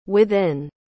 ¿Cómo se pronuncia correctamente within?
La pronunciación de within es /wɪˈðɪn/, con énfasis en la segunda sílaba. Ese sonido ð es el mismo que en palabras como this o that.